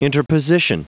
Prononciation du mot interposition en anglais (fichier audio)
Prononciation du mot : interposition